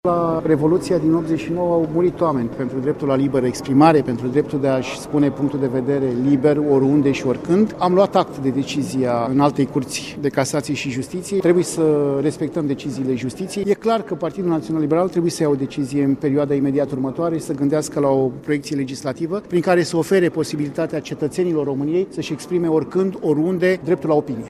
PNL are în vedere o iniţiativă legislativă privind garantarea dreptului la liberă exprimare al cetăţenilor, a anunţat deputatul liberal Gigel Ştirbu:
Gigel-Stirbu.mp3